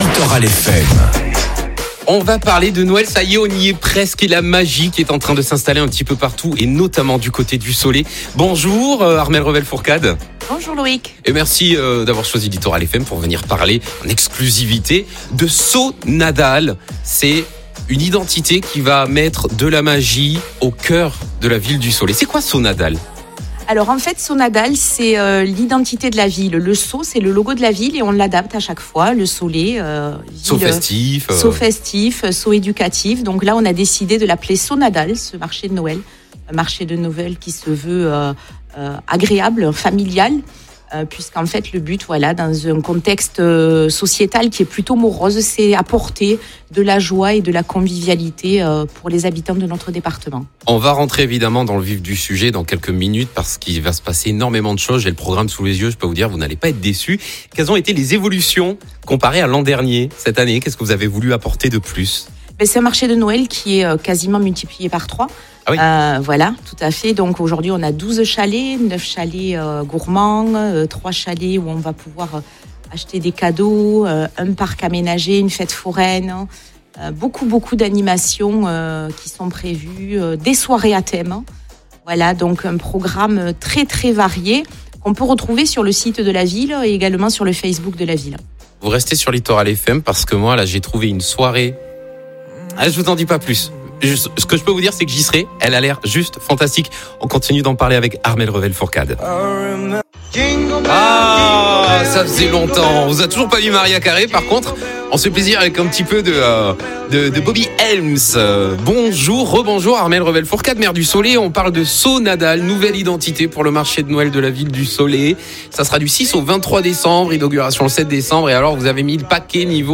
Écoutez vite notre échange avec la maire, qui nous partage les coulisses et l’ambiance chaleureuse qui font de So Nadal un marché de Noël à ne pas manquer.